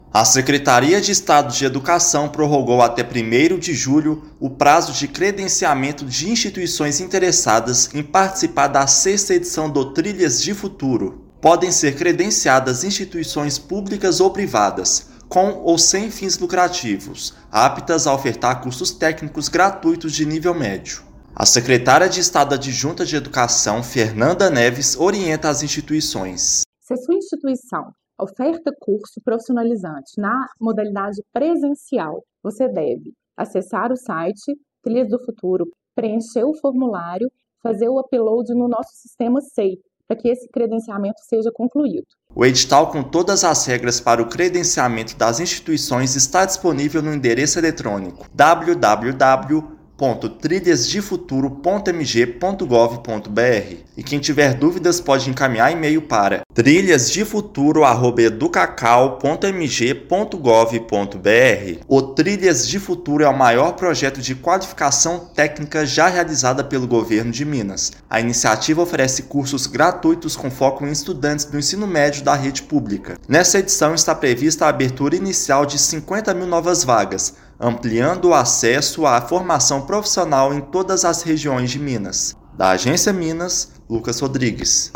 Instituições têm até o dia 1/7 para participar do projeto que vai ofertar 50 mil vagas em cursos técnicos gratuitos. Ouça matéria de rádio.